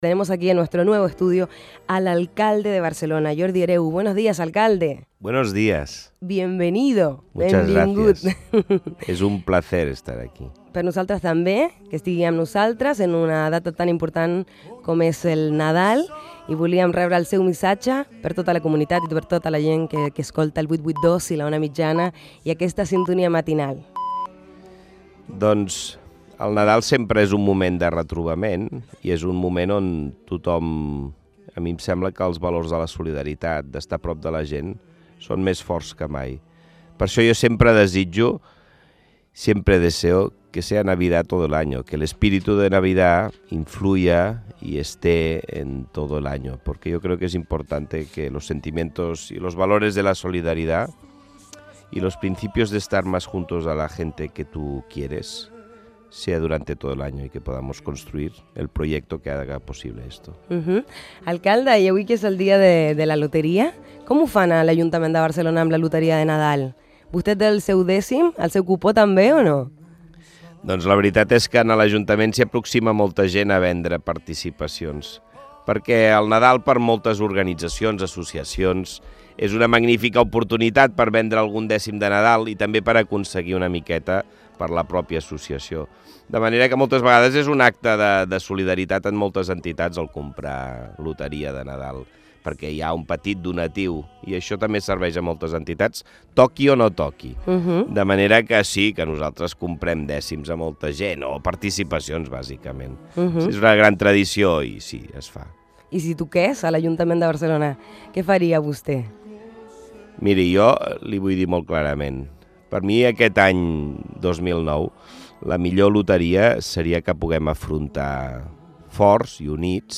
Fragment d'una entrevista a l'alcalde de Barcelona, Jordi Hereu, dies abans de les festes de Nadal.
Entreteniment